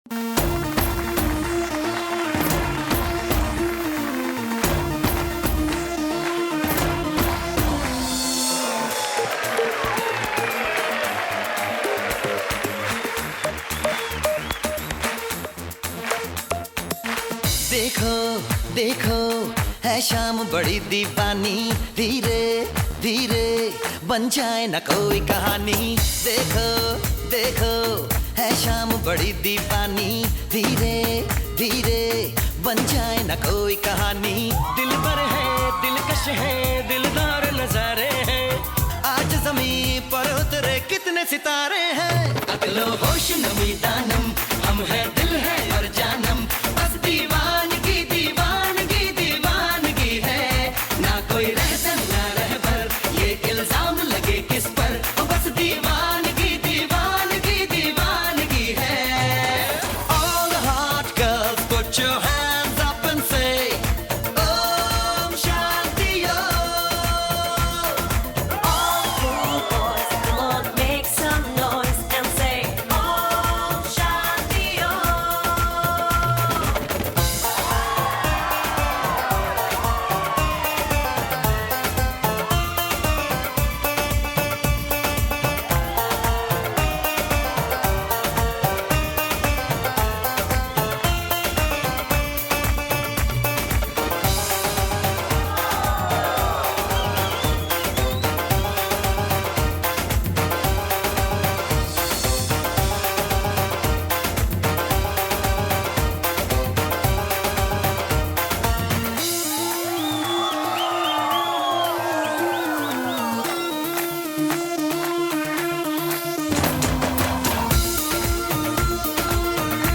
Carpeta: musica hindu mp3